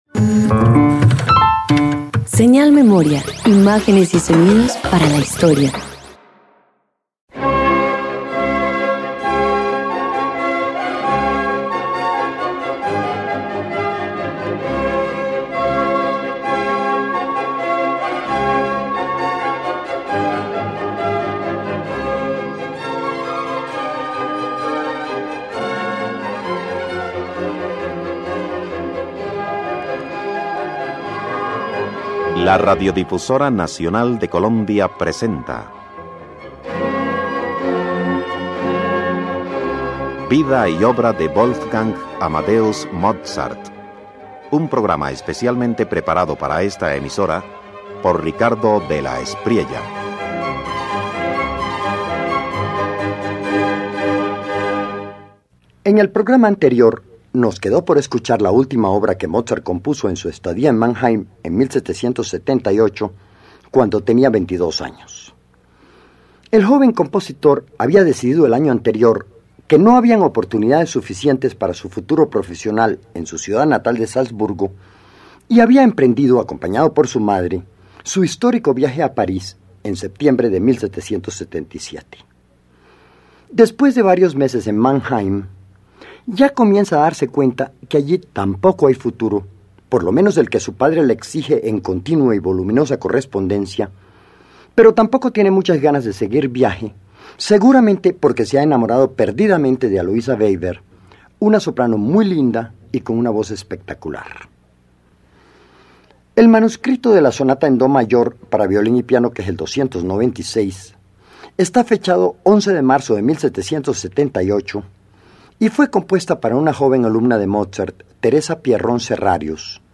Mozart aprovecha su estadía en Mannheim para componer la Sonata en do mayor para violín y piano K296, inspirada por la joven Teresa Pierrón. Un estilo que combina frescura y equilibrio, anticipando la elegancia de sus futuras obras vienesas.